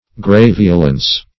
Search Result for " graveolence" : The Collaborative International Dictionary of English v.0.48: Graveolence \Gra*ve"o*lence\, n. [L. graveolentia: cf. F. grav['e]olence.